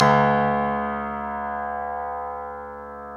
Index of /90_sSampleCDs/Club-50 - Foundations Roland/PNO_xTack Piano/PNO_xTack Pno 1M